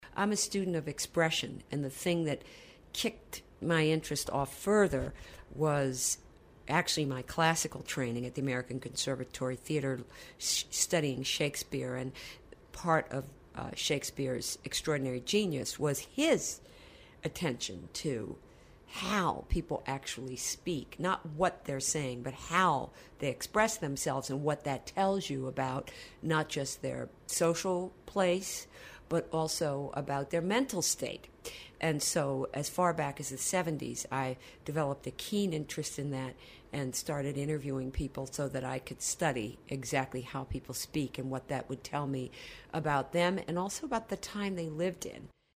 Transcript of conversation with Anna Deveare Smith